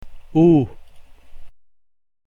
The preceding vowel now occurs with a macron, implying, as before, that the new sound that we have, is an extension of the basic sound U. This symbol has the same sound as the combination "OO" in the English word MOON.